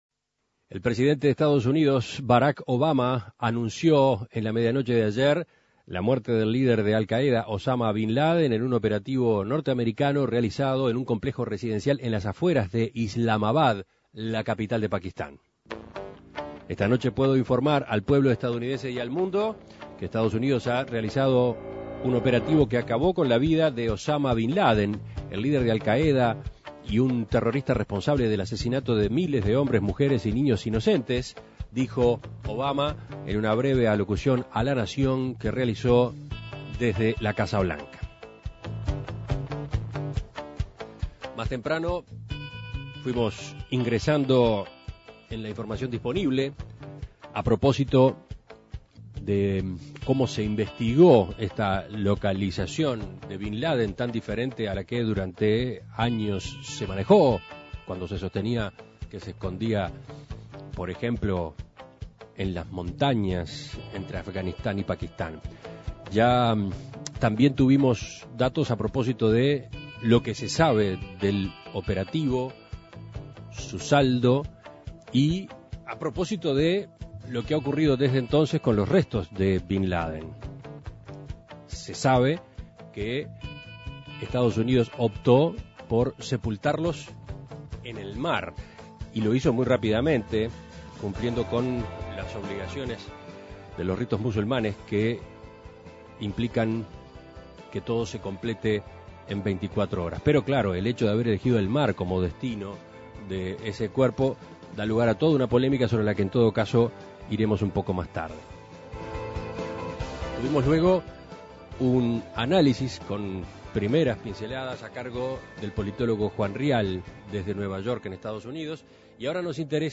Escuche el contacto con el politólogo